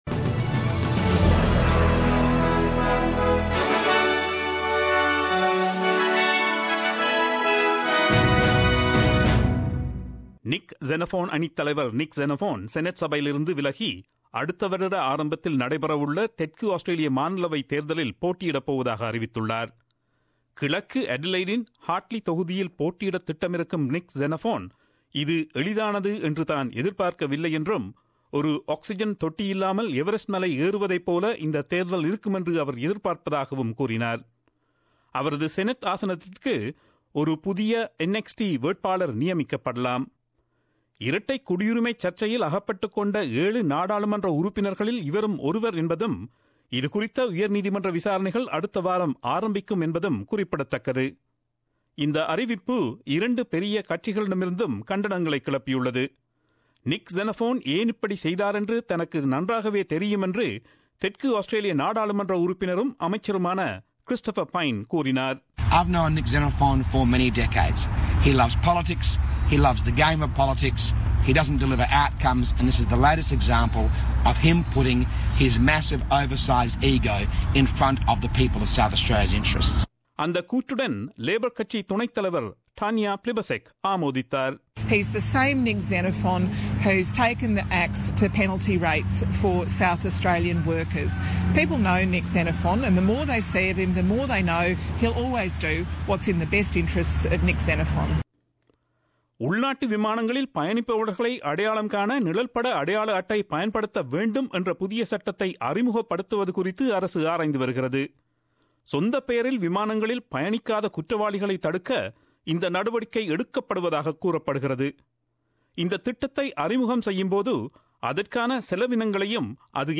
SBS Tamil News
Australian news bulletin aired on Friday 06 October 2017 at 8pm.